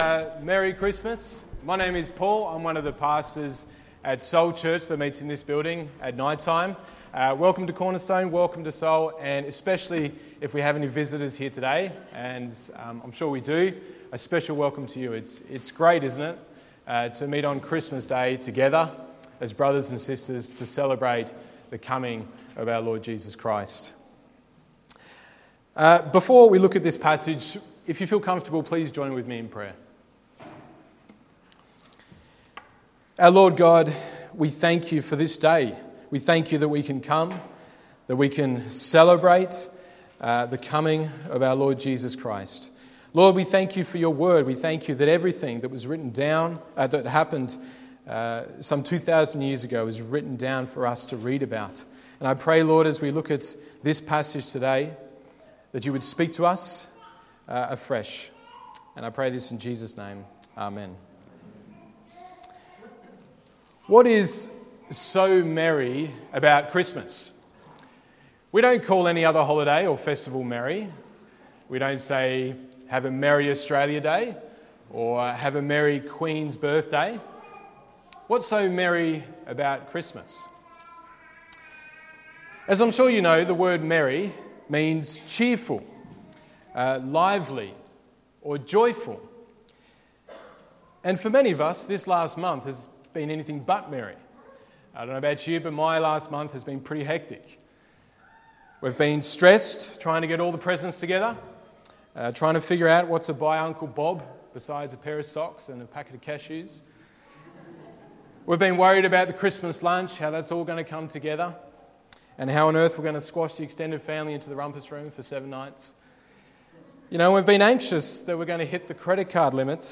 Christmas Day Service - Message: This is how the birth of Christ came about...